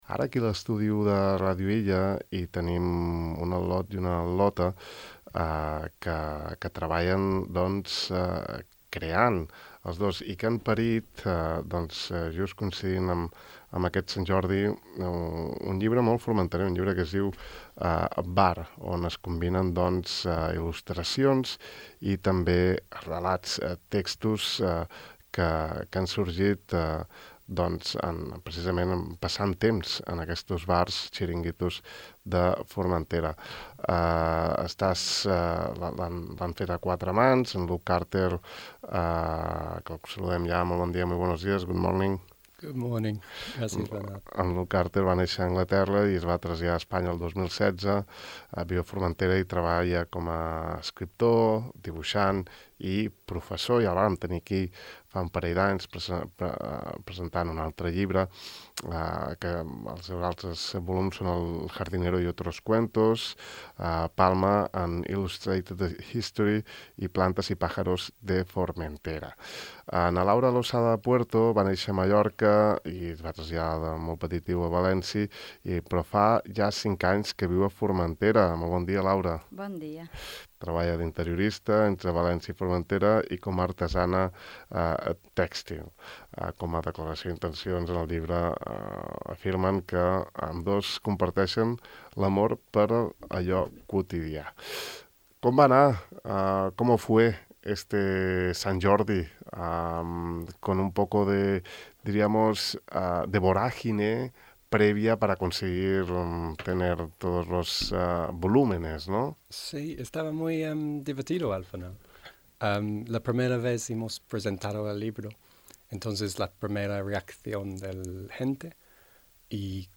Aquí podeu escoltar, sencera, l’entrevista que els hem fet aquest matí: